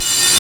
HAT TEK H1HR.wav